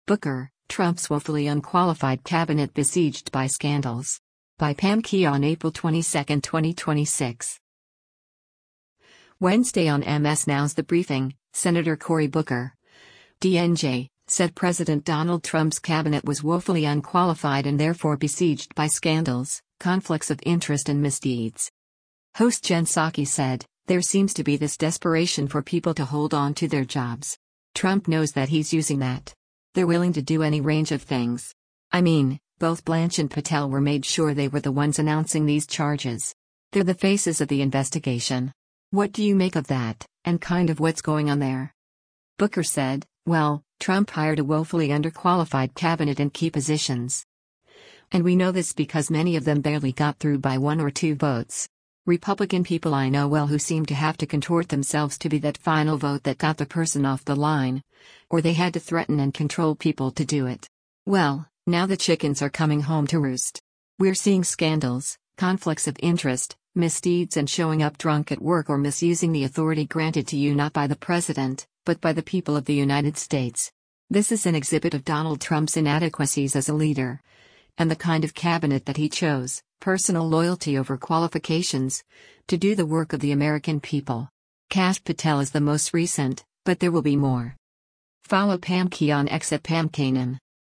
Wednesday on MS  NOW’s “The Briefing,” Sen. Cory Booker (D-NJ) said President Donald Trump’s cabinet was “woefully unqualified” and therefore besieged by scandals, conflicts of interest and misdeeds.